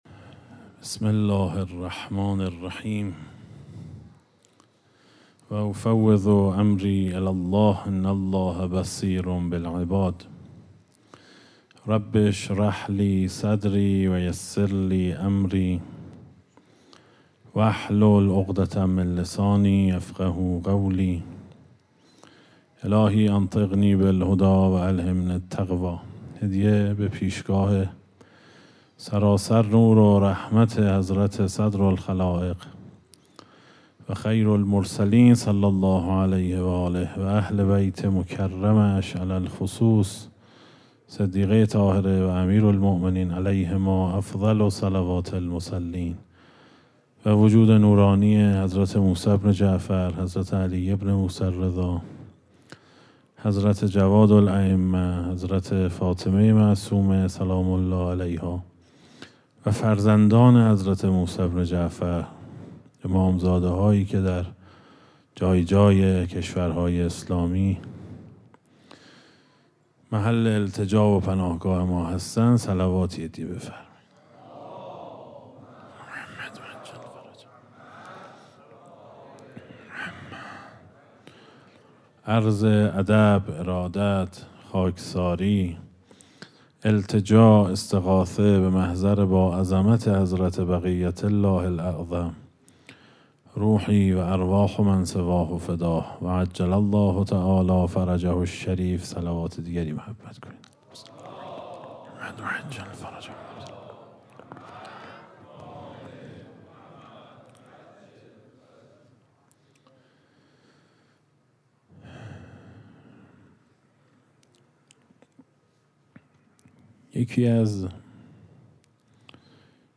در آستان مقدس امامزاده صالح علیه السلام
اشتراک گذاری دسته: امام زمان ارواحنا فداه , سخنرانی ها قبلی قبلی منبر فضائل علوی در آستان رضوی؛ جلسه پنجم